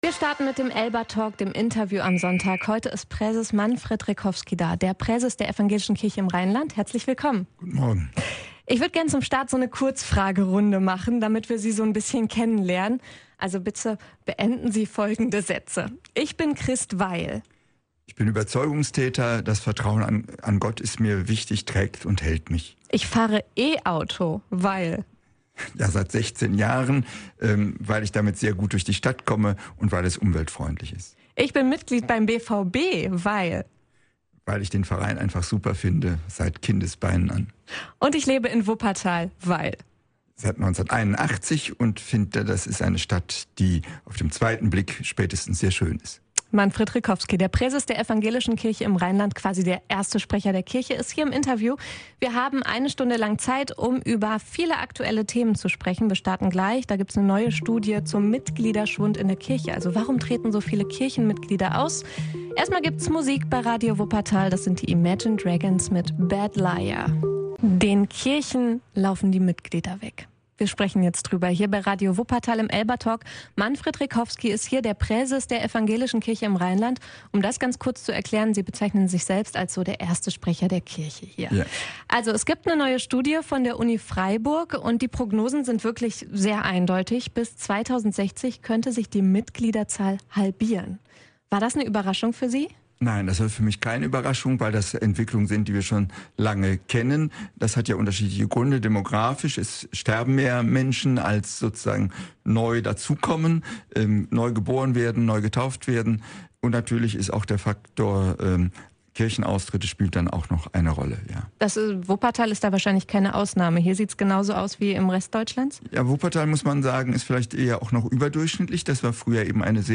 ELBA-Talk mit Manfred Rekowski